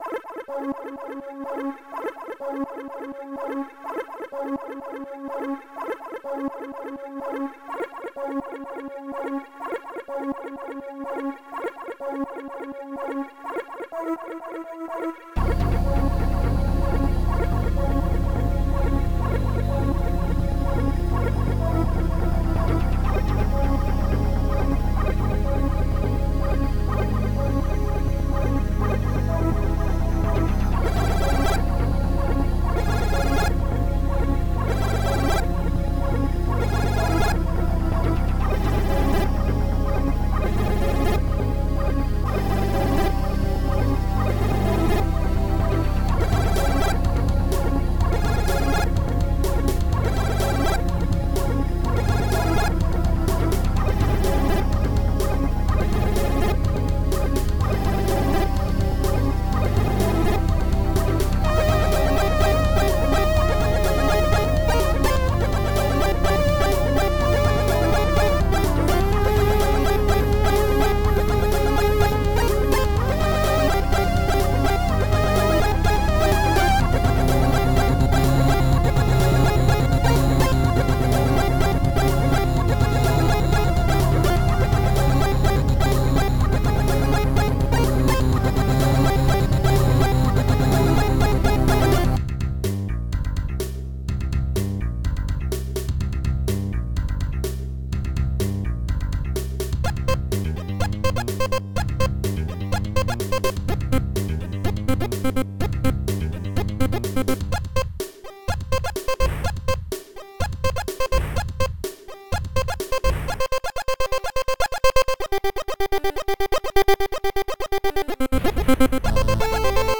chiptune somewhat incoherent,,